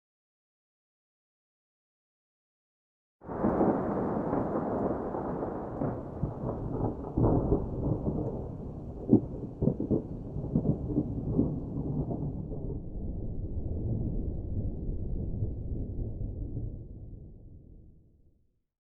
storm_2.ogg